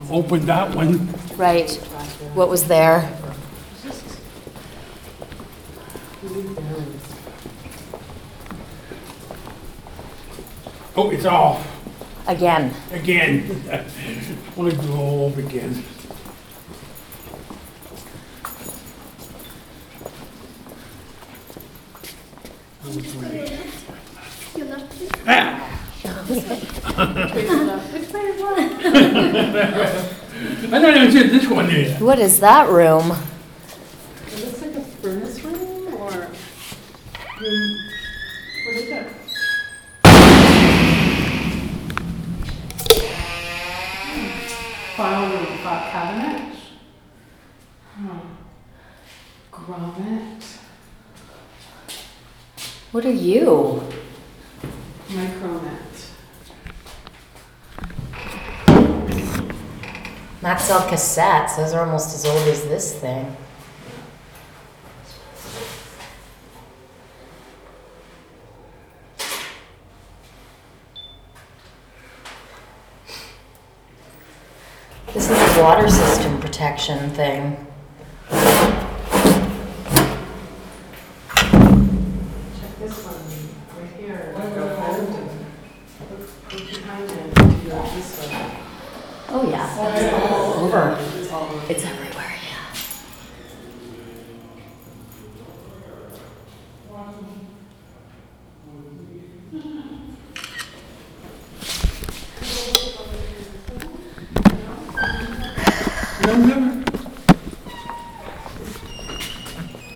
HRC Ambient Sounds